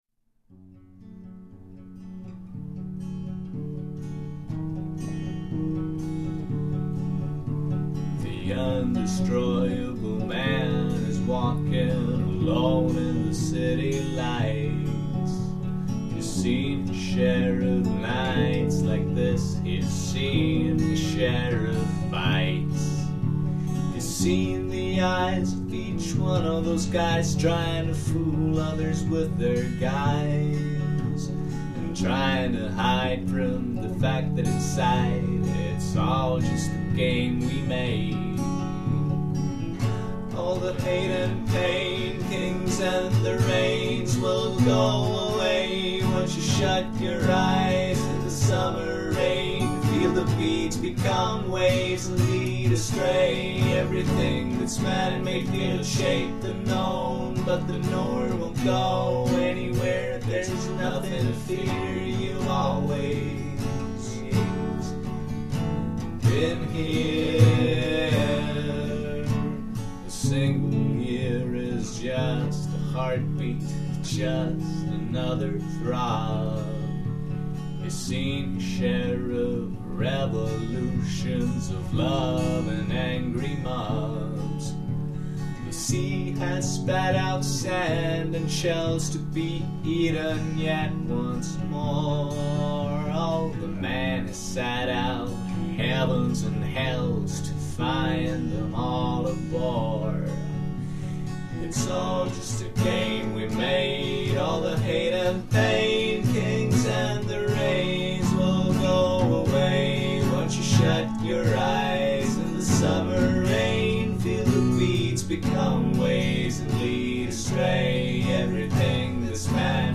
Kusjuures teatud nüansside tõttu helitöötluses on seda lugu vast kõige mõnusam kõrvaklappidega kuulata.